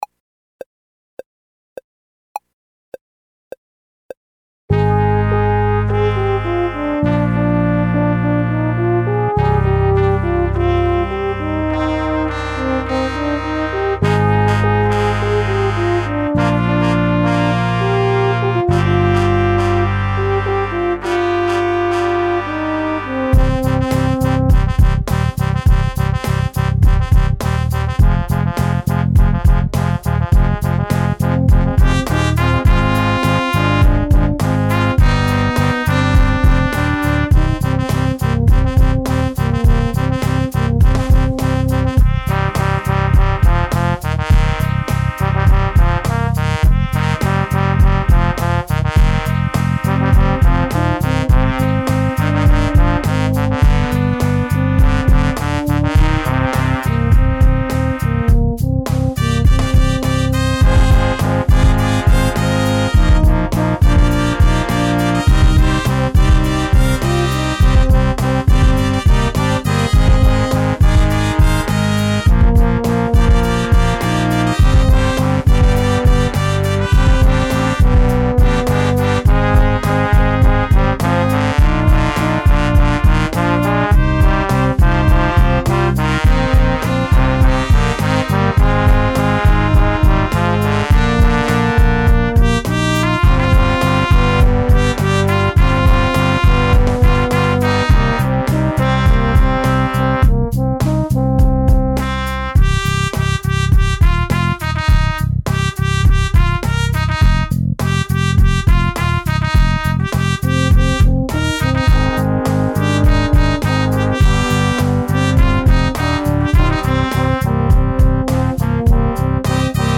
Trp 1 Trp 2 Pos 1 Pos 2 Horn BDrum Lyr Sax